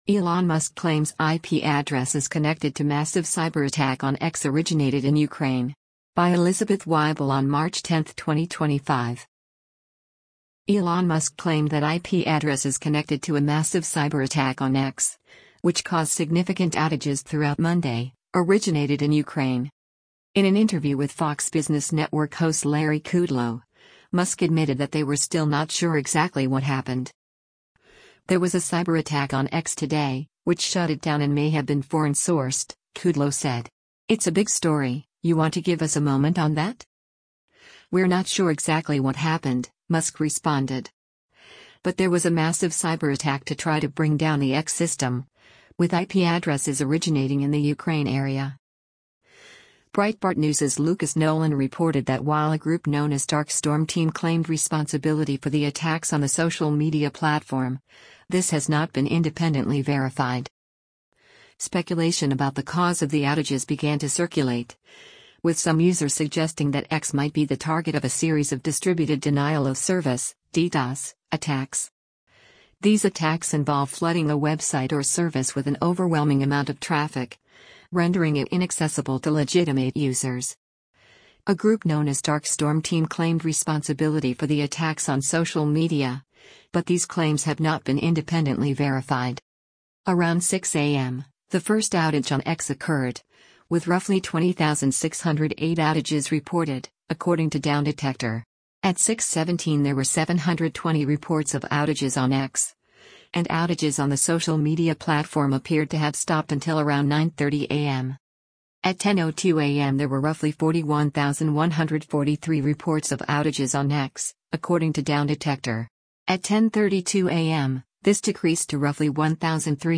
In an interview with Fox Business Network host Larry Kudlow, Musk admitted that they were still “not sure exactly what happened.”